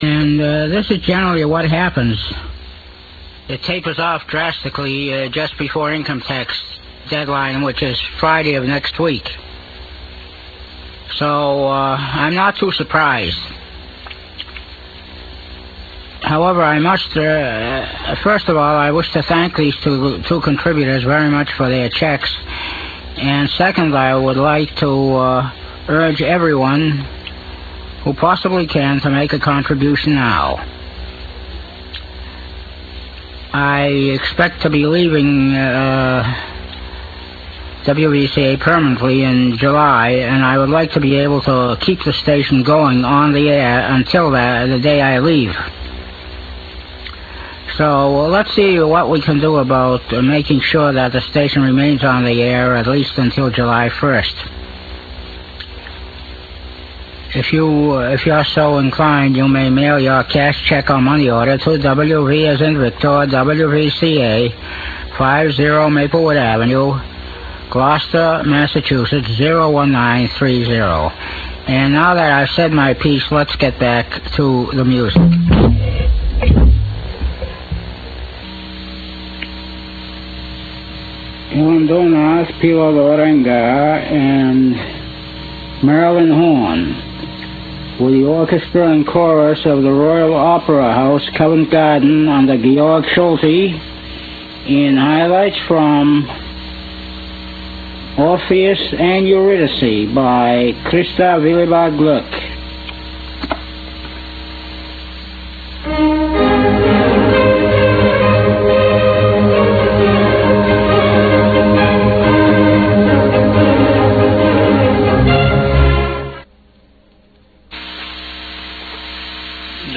He was probably the most famous "one-man show" in radio history.